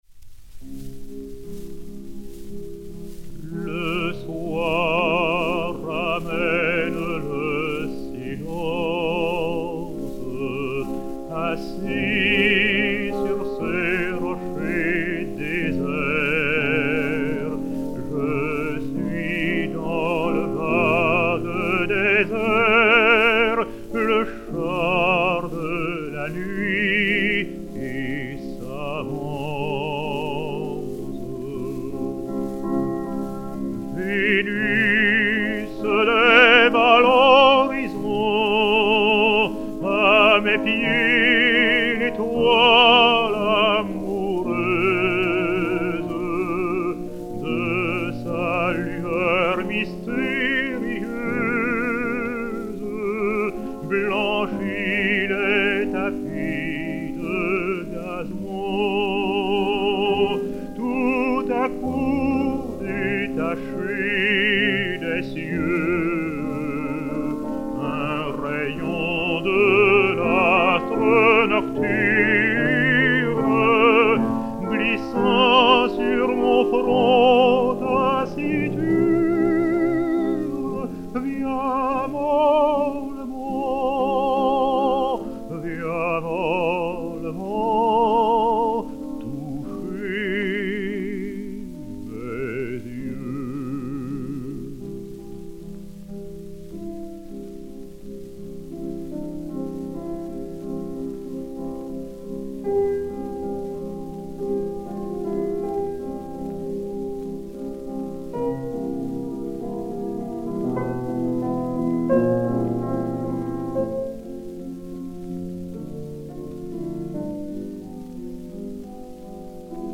piano
Gramophone DB 4971, mat. 2LA644-1, enr. au Studio Albert à Paris le 02 octobre 1935